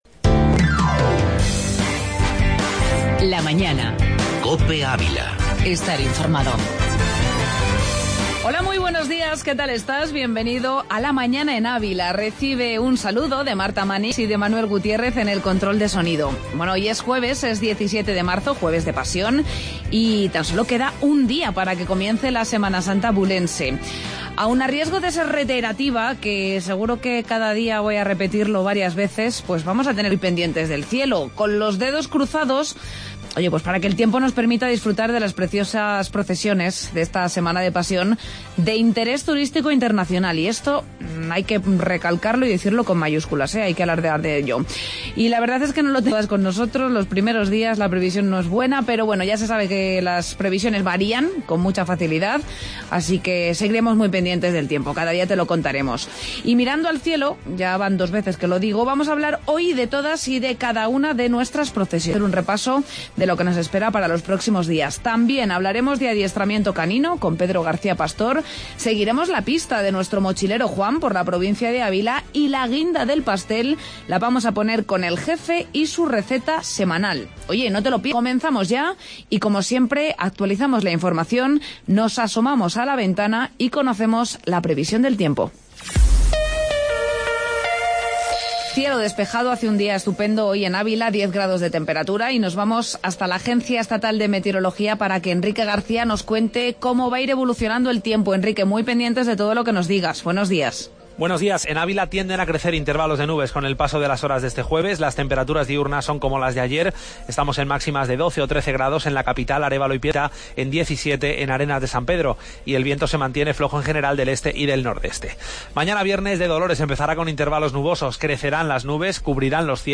AUDIO: Entrevista Semana Santa y Buen ciudadano canino